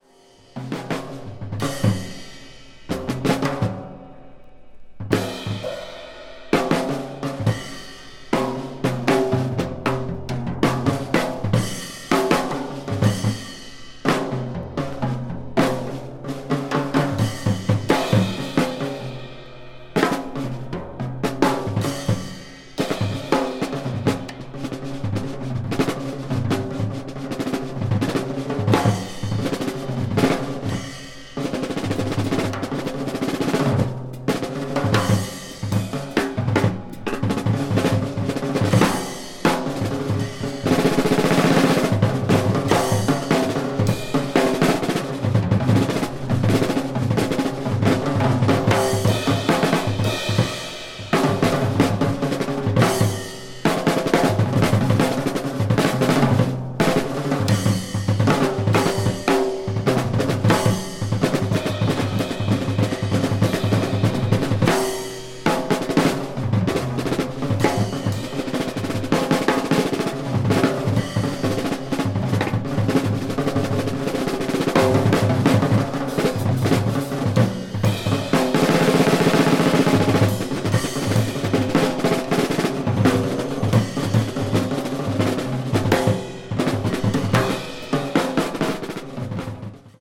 media : EX/EX(some slightly surface noises.)